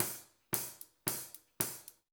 DWS HI-HAT-L.wav